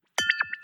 ting-dualchannel48-expected.wav